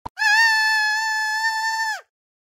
Clash Royale Hog Rider Scream Soundboard: Play Instant Sound Effect Button
This high-quality sound effect is part of our extensive collection of free, unblocked sound buttons that work on all devices - from smartphones to desktop computers.